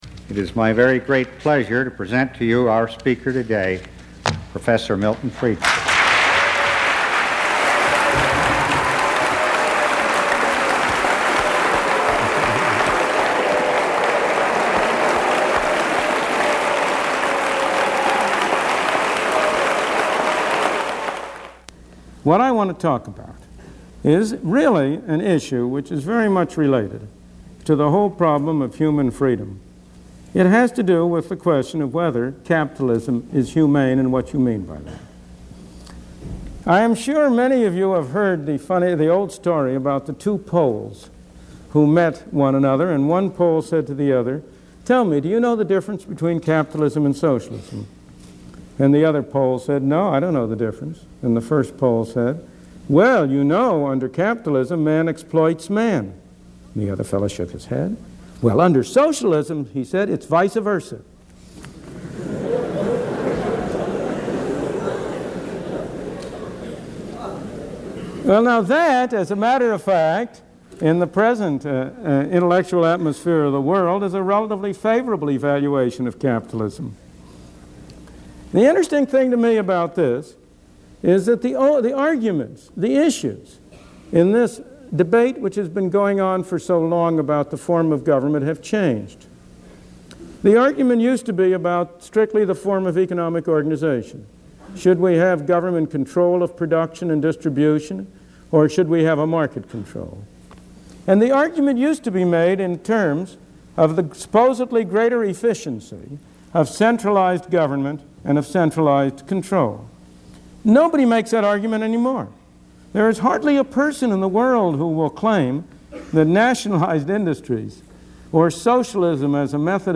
Episode 3 - Milton Friedman Speaks - Is Capitalism Humane?